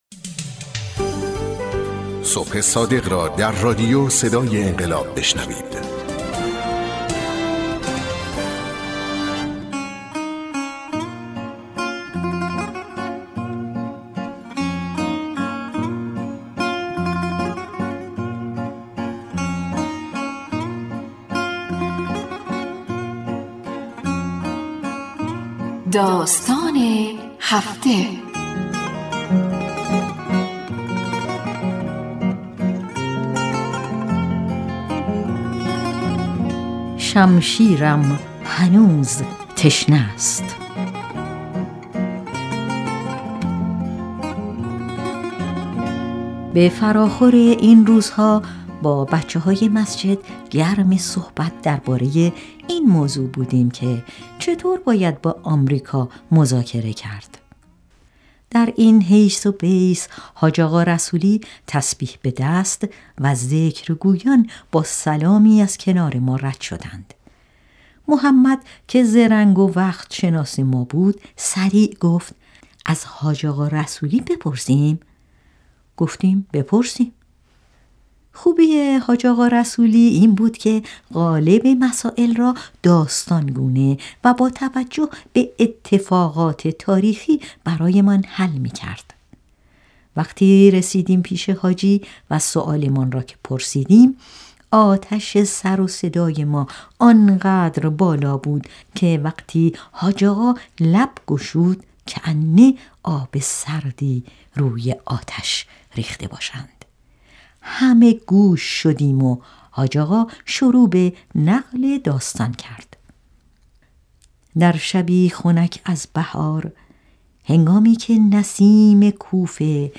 رادیو صدای انقلاب 1800 | داستان: شمشیرم هنوز تشنه است